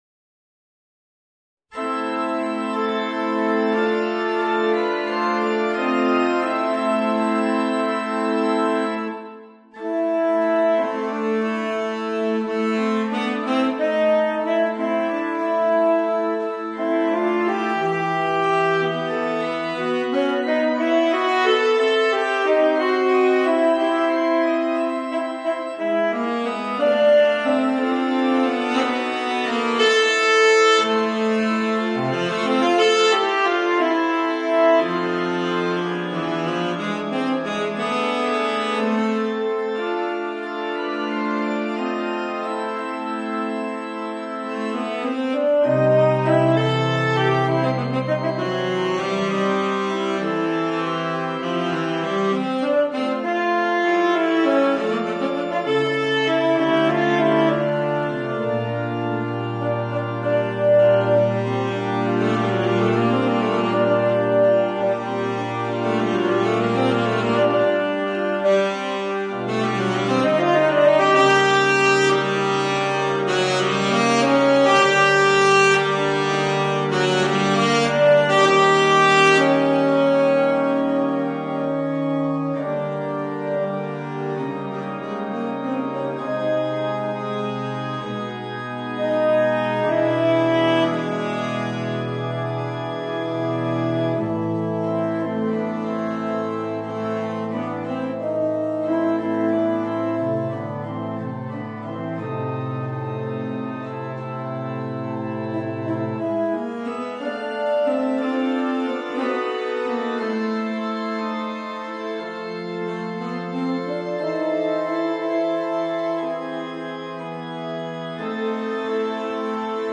Saxophone ténor & orgue